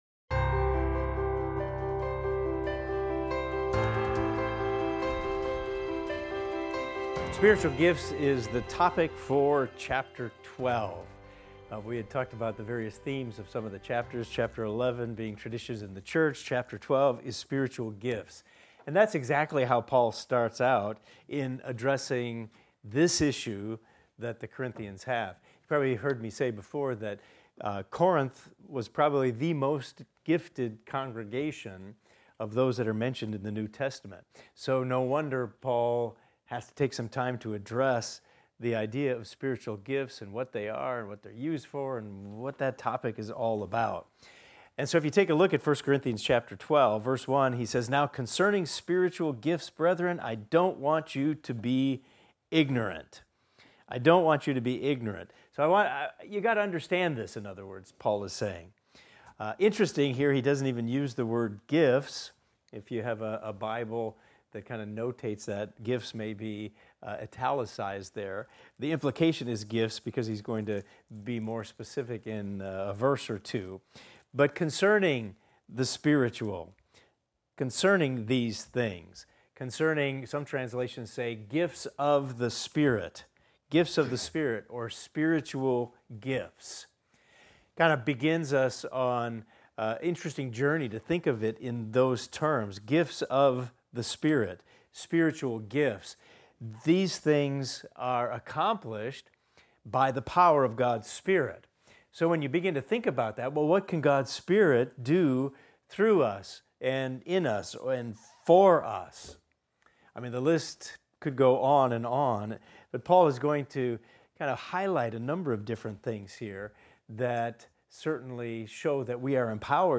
In this class we will discuss 1 Corinthians 12:1–11 and examine the following: Paul discusses spiritual gifts, emphasizing their diverse nature and common origin from the Holy Spirit. He underscores the importance of understanding and honoring the variety of gifts within the body of Christ.